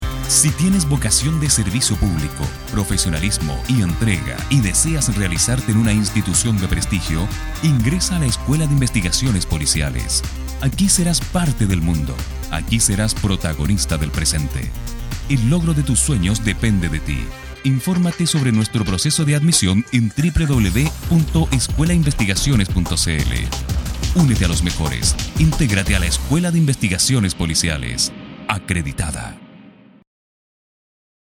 :: Escuchar :: Locución Radial Admisión 2012
Locucion_Radial_Admision.mp3